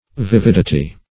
Search Result for " vividity" : The Collaborative International Dictionary of English v.0.48: vividity \vi*vid"i*ty\ (v[i^]*v[i^]d"[i^]*t[y^]), n. The quality or state of being vivid; vividness.
vividity.mp3